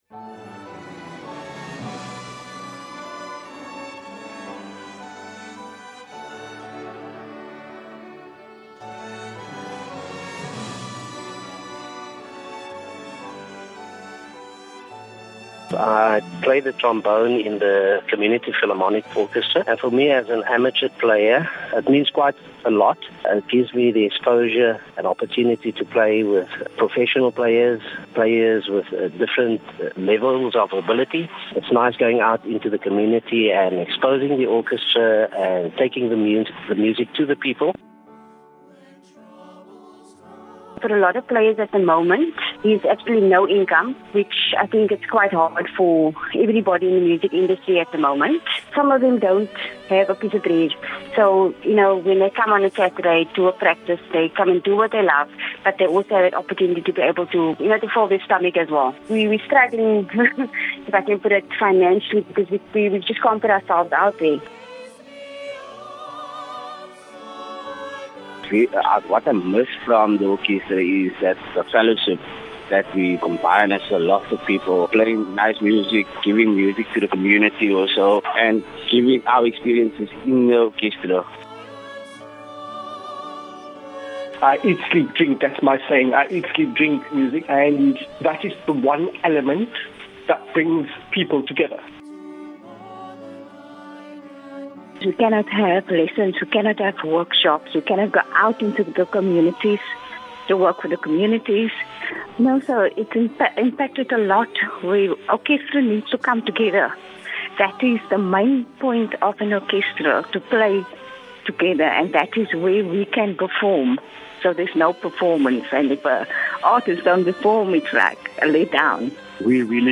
Some of the members of the orchestra told Smile Breakfast about what being involved with the rest of the players means to them, and how the pandemic has affected the much needed work that they do: